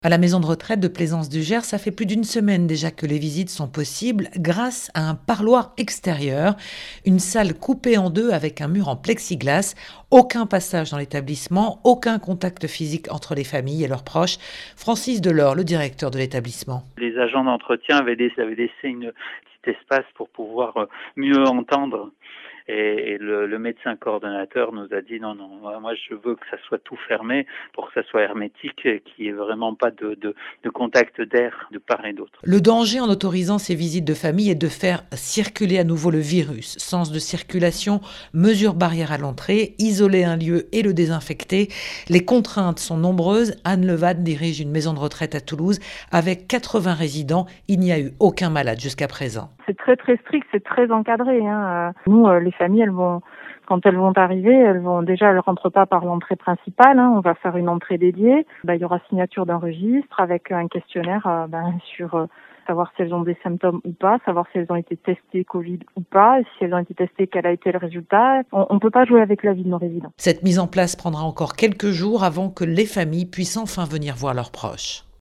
Reportage dans le Gers